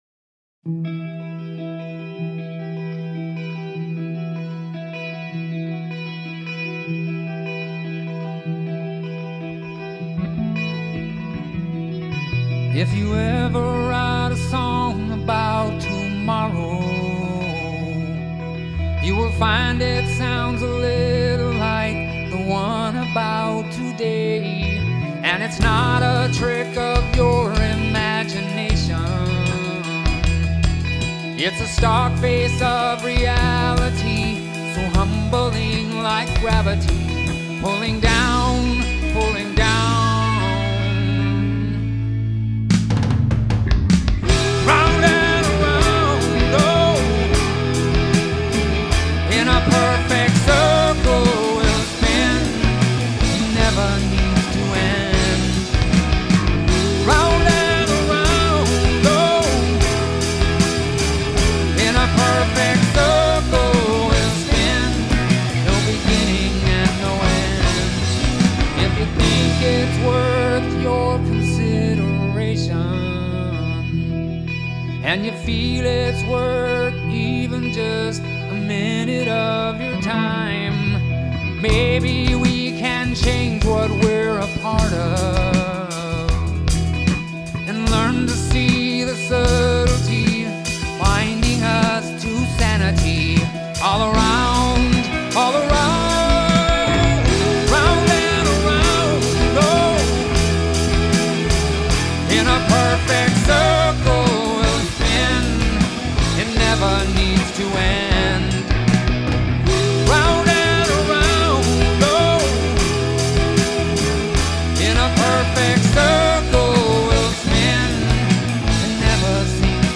This was an attempt at recording a reasonable quality demo track in my home studio, for our band at the time Six Mile Cross.  In this track I'm playing bass, and the guitar solo.
backing vocals
rhythm guitar
Recorded using Sony ACID Pro in 2002: